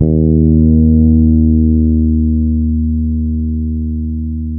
-MM JACO D#3.wav